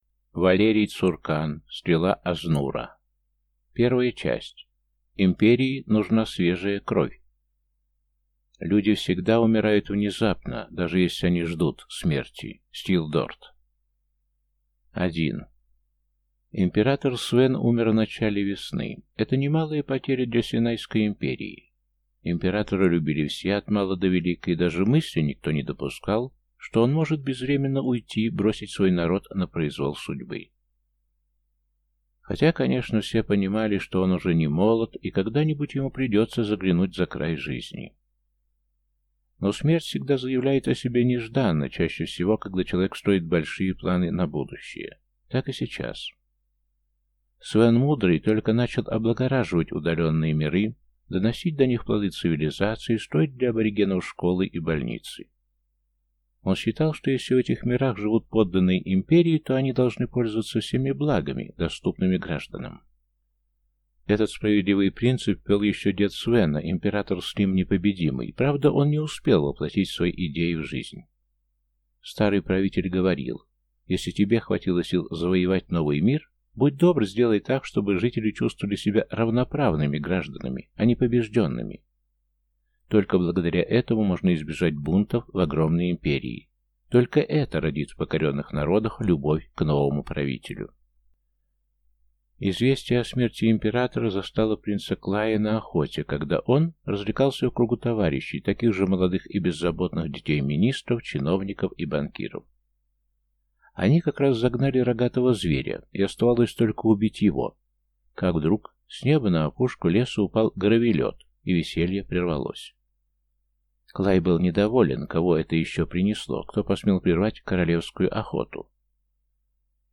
Аудиокнига Стрела Азнура | Библиотека аудиокниг